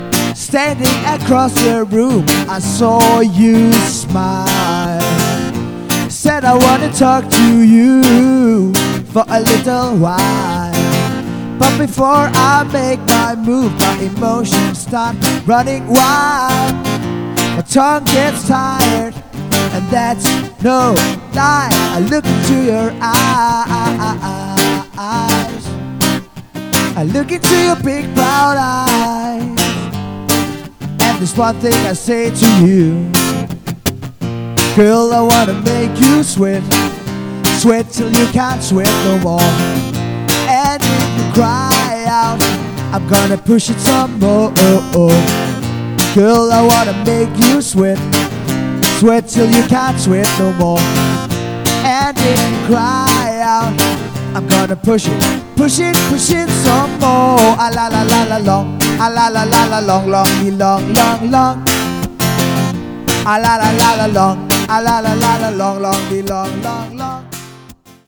• Coverband
• Solomusiker